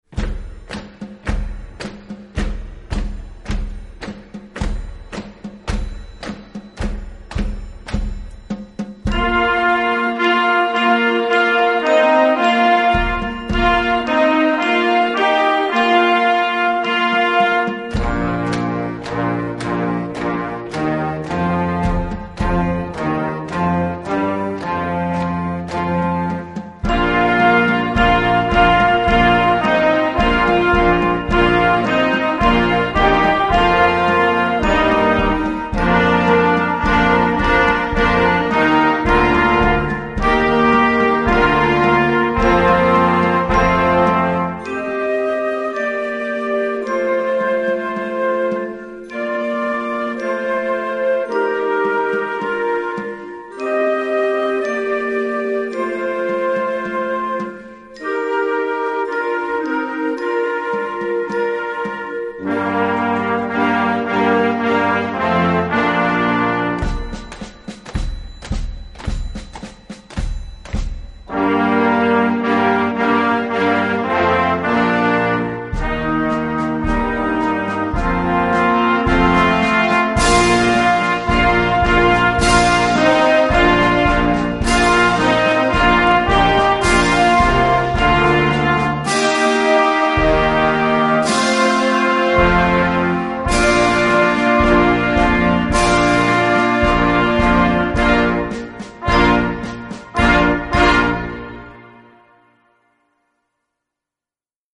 Gattung: Blasmusik für Jugendkapelle
Besetzung: Blasorchester